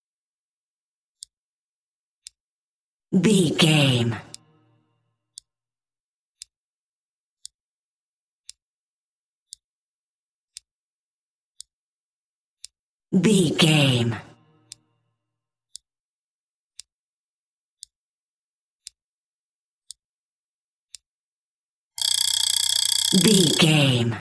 Mechanic alarm clock
Sound Effects
urban